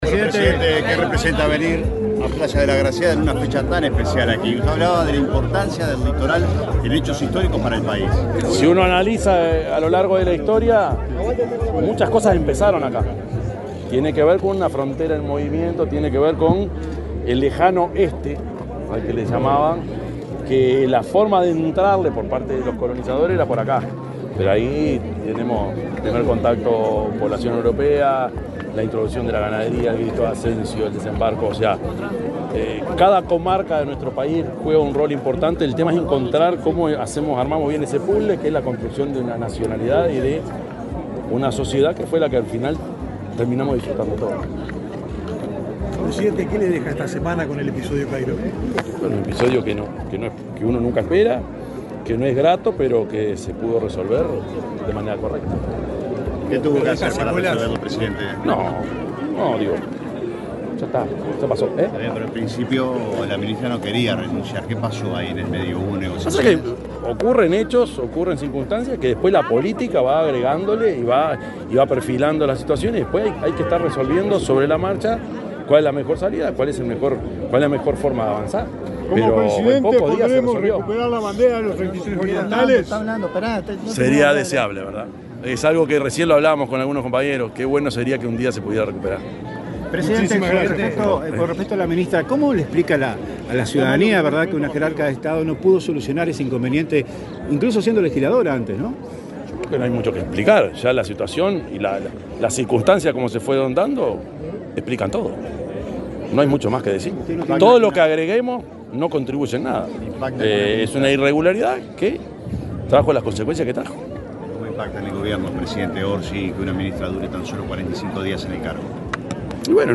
Declaraciones del presidente de la República, profesor Yamandú Orsi
Este sábado 19 de abril, en la playa de la Agraciada, departamento de Soriano, el presidente de la República, Yamandú Orsi, participó del acto
Luego, dialogó con la prensa.